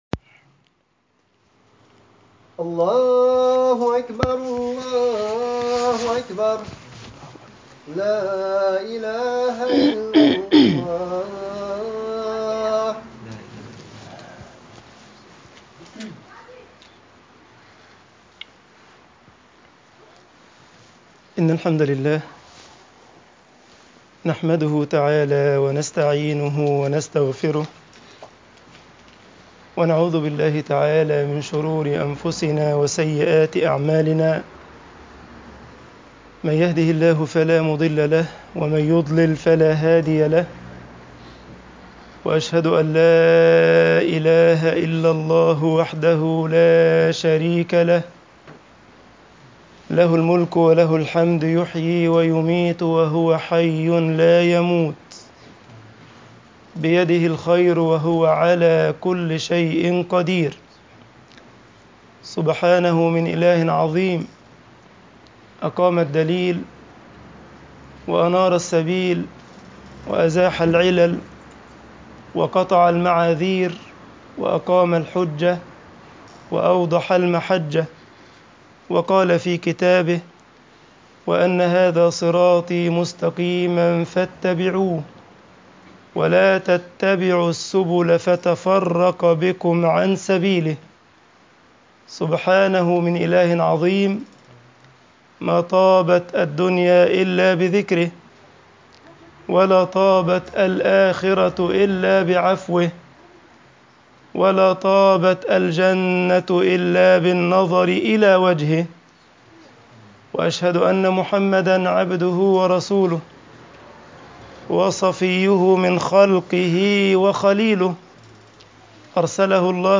خطب الجمعة والعيد
Freitagsgebet_wabashir almukhbitin.mp3